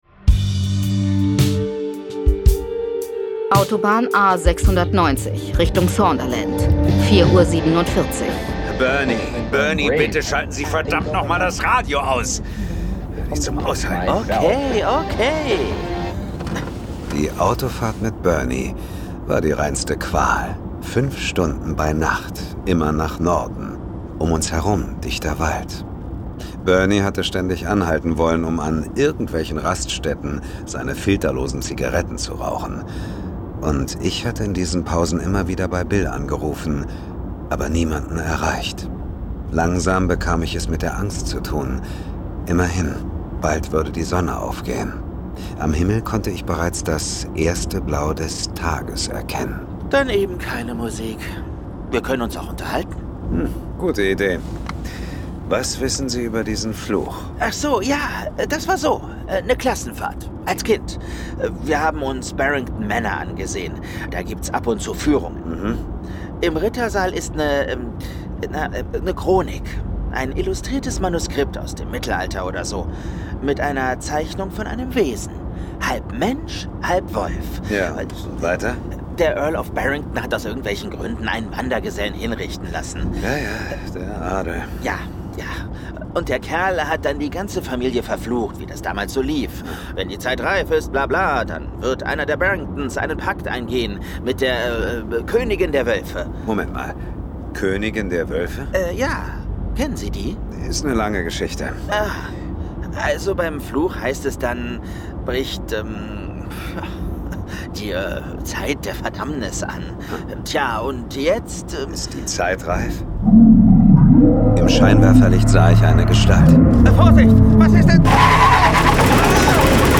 John Sinclair - Folge 180 Horror-Hochzeit. Hörspiel. Jason Dark (Autor) Dietmar Wunder , diverse (Sprecher) Audio-CD 2025 | 1.